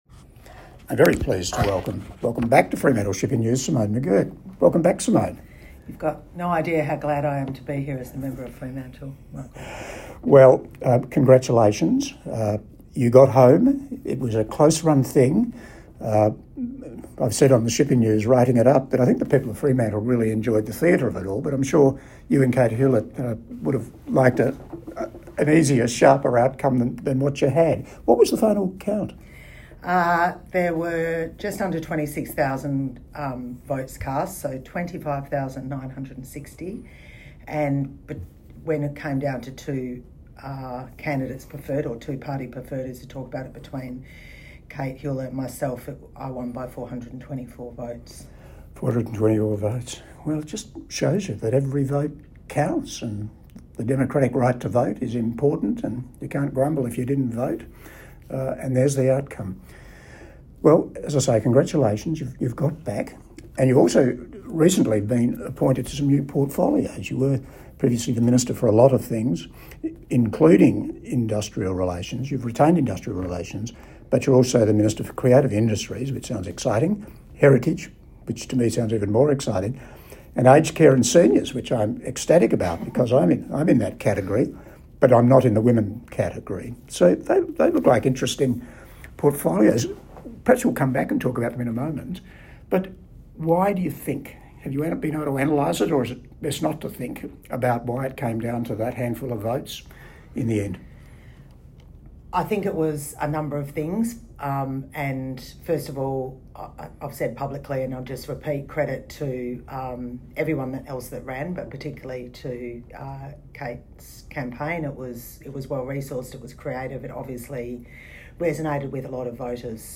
Interview with Simone McGurk, Re-elected MLA for Fremantle - The Debrief - Fremantle Shipping News